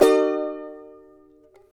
CHAR E MJ  D.wav